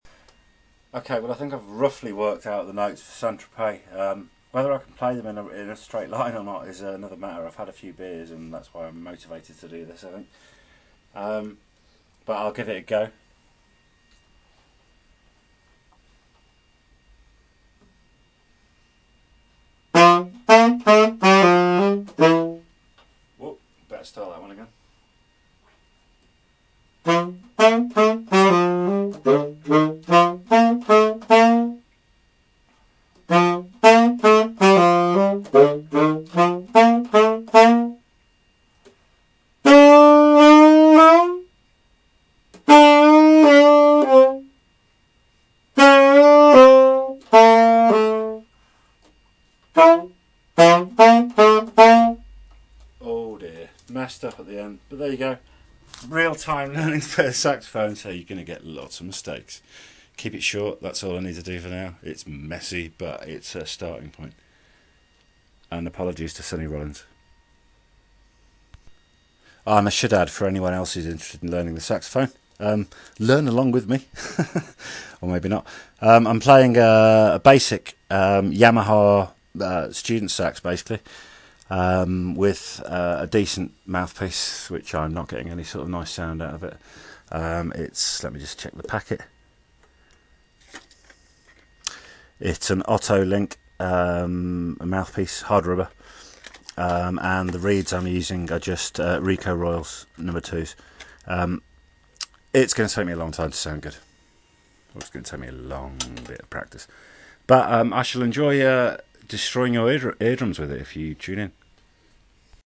Tenor Practice 1 St Tropez